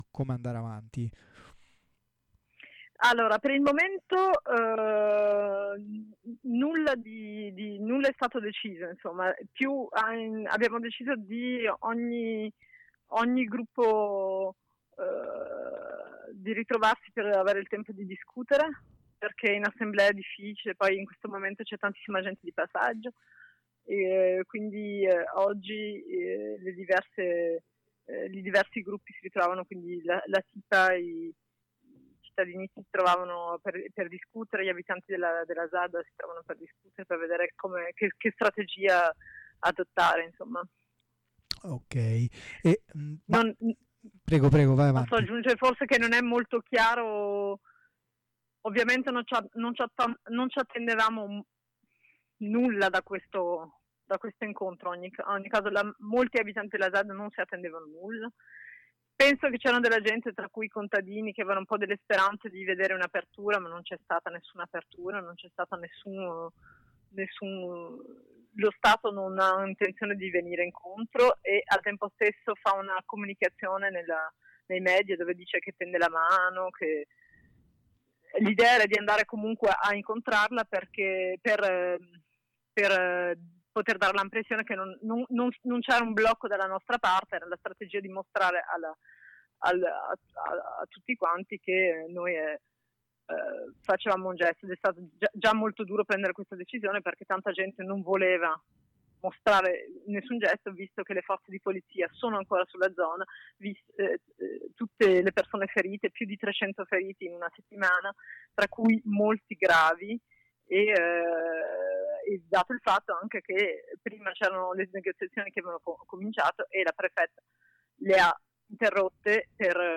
al telefono